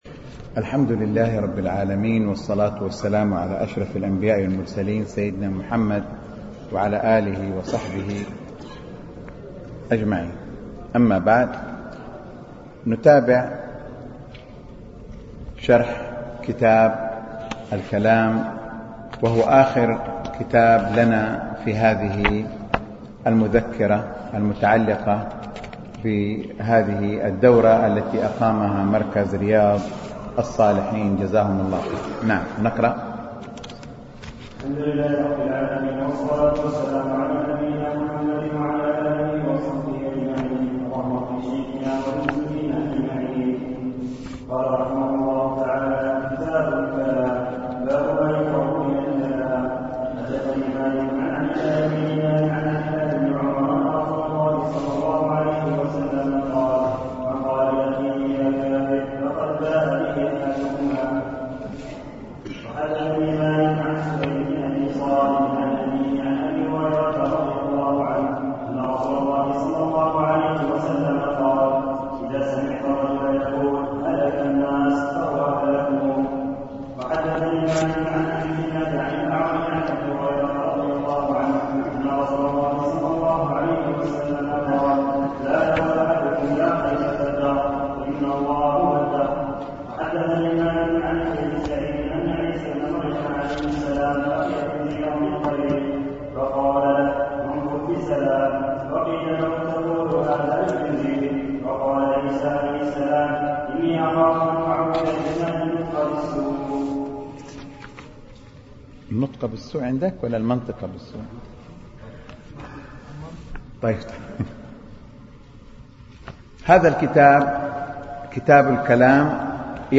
شرح أبواب من الموطأ للإمام مالك - الدرس التاسع (كتاب الكلام)
دروس مسجد عائشة (برعاية مركز رياض الصالحين ـ بدبي)